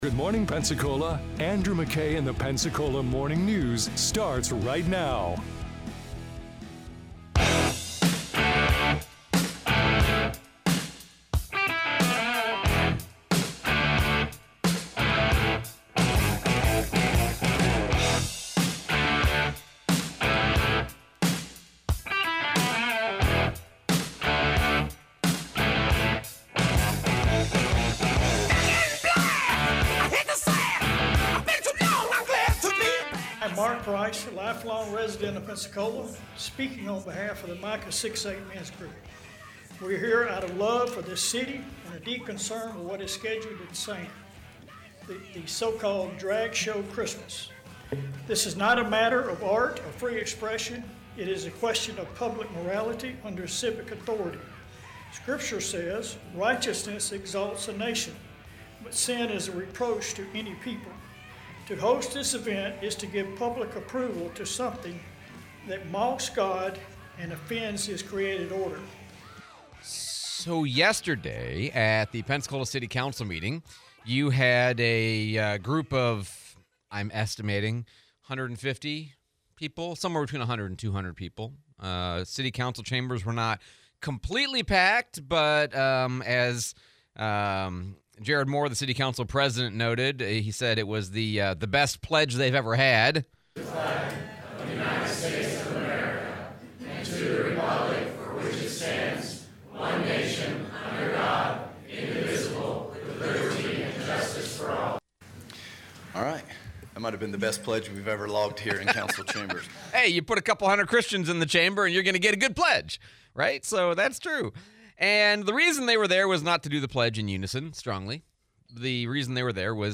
Pensacola City Council meeting, Replay of Alex Andrade interview